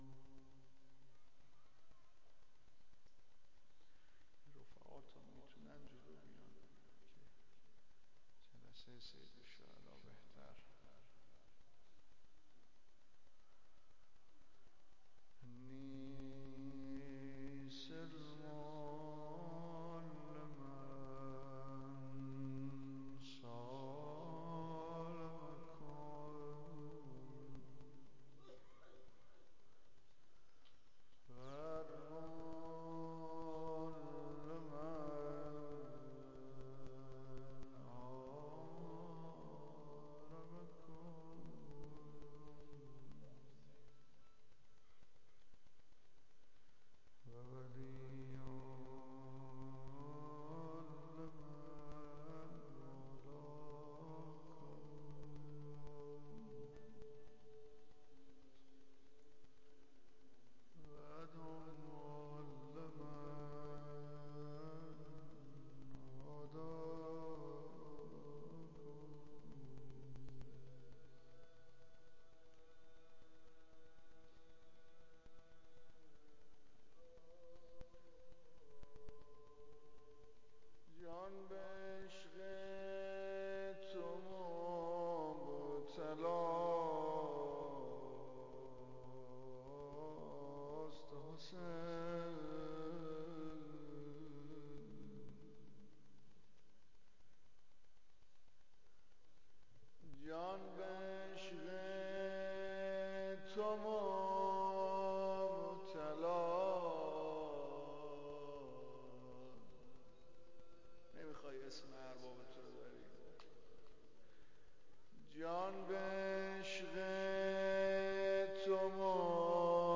باب الحوائج - مدّاح شب دوم محرم 96